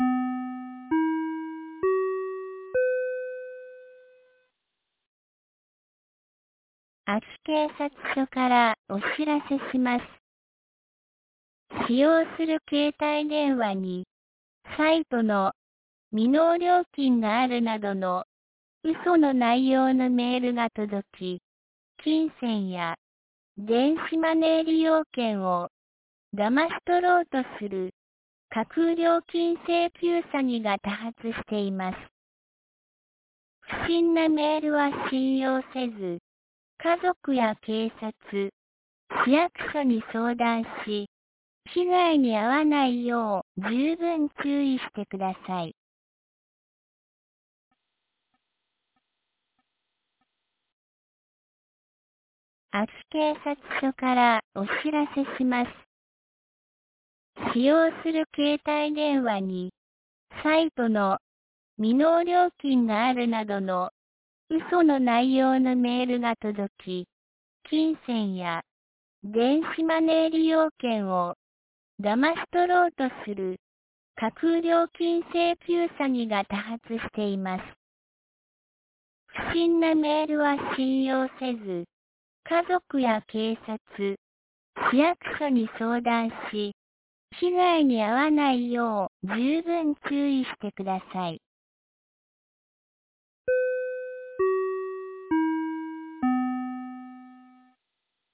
2024年06月08日 17時11分に、安芸市より全地区へ放送がありました。